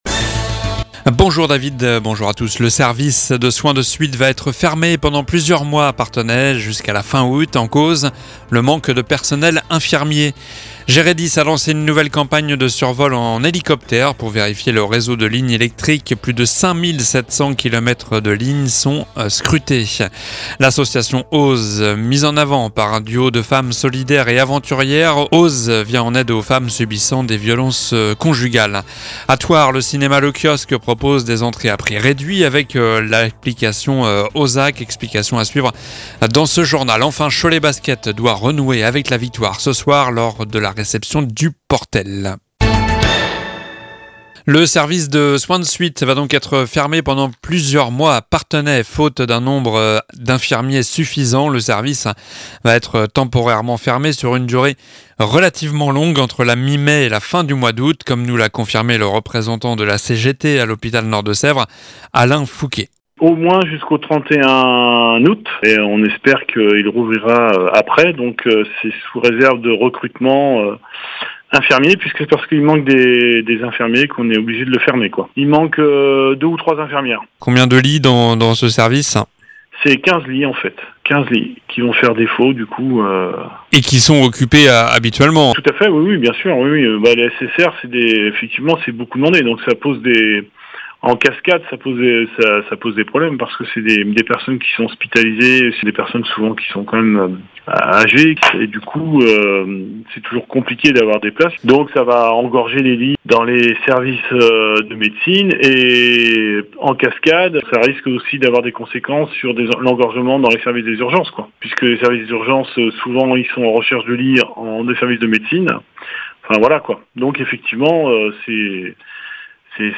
Journal du mardi 09 mai (midi)